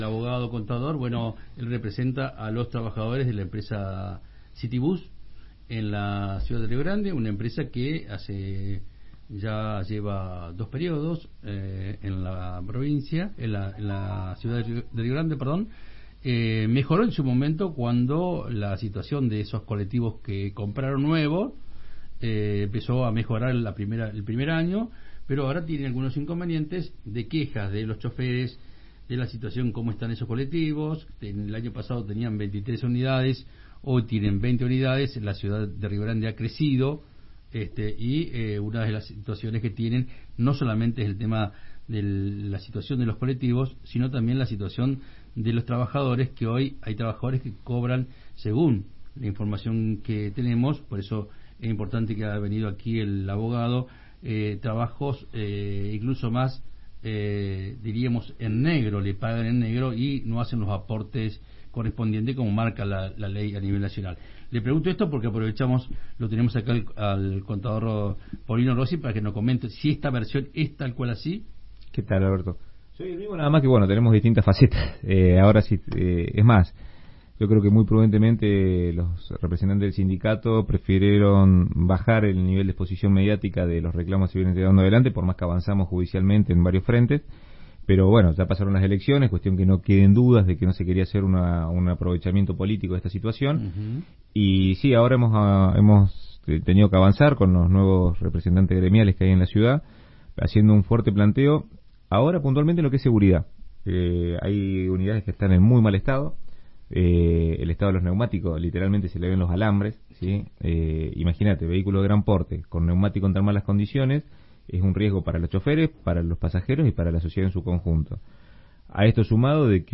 visitó los estudios de Radio Universidad 93.5 y Provincia 23